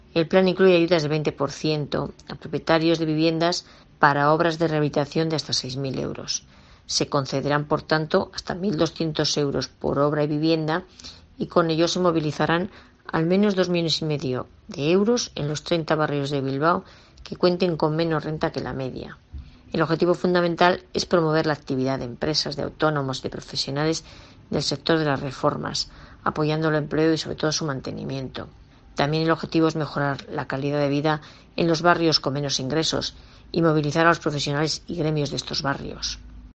Gloria Múgica, concejala bilbaína de regeneración urbana